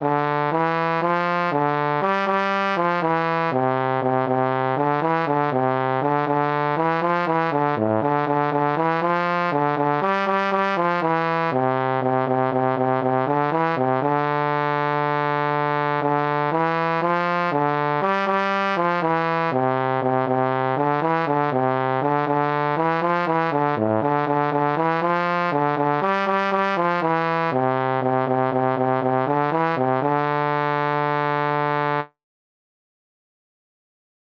Jewish Folk Song (part of Chanukah davening)
D minor ♩= 120 bpm